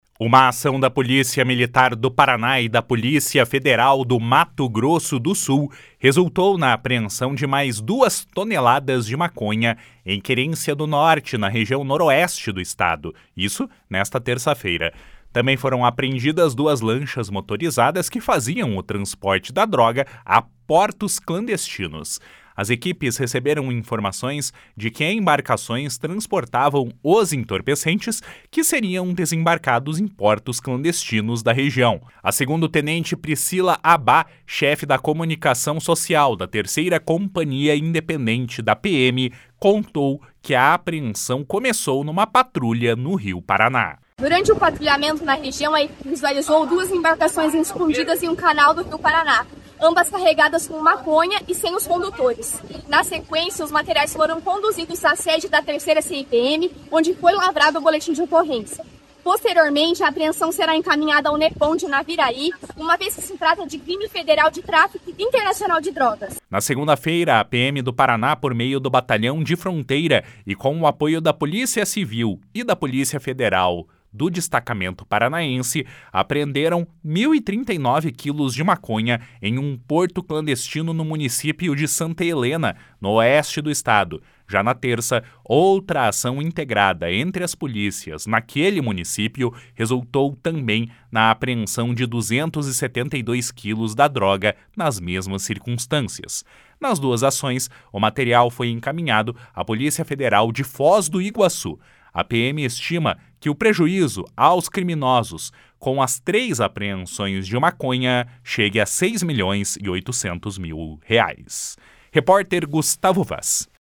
A PM estima que o prejuízo aos criminosos com as três apreensões de maconha chegue a seis milhões e 800 mil reais. (Repórter